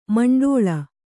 ♪ maṇḍōḷa